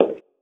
inside-step-4.wav